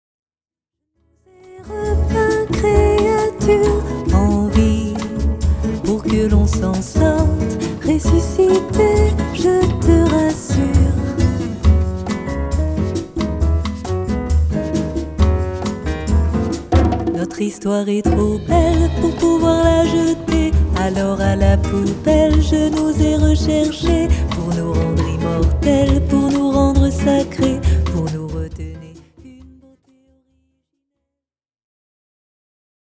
Une voix qui enchante...